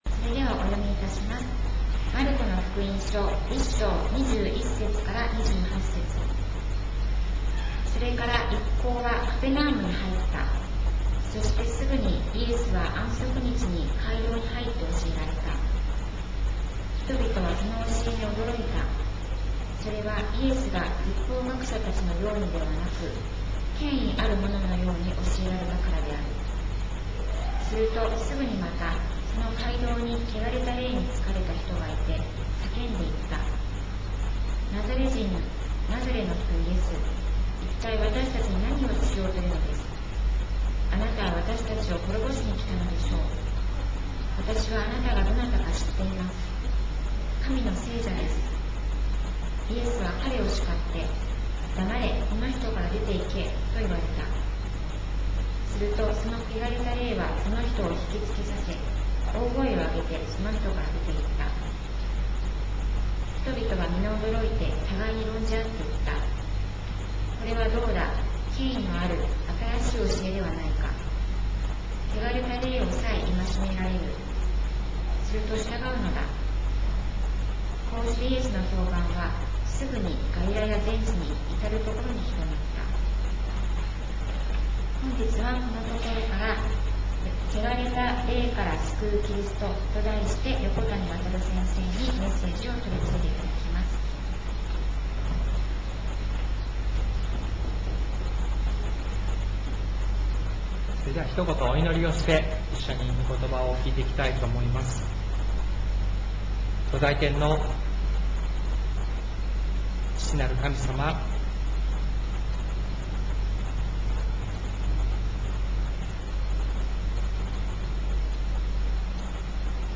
礼拝メッセージ集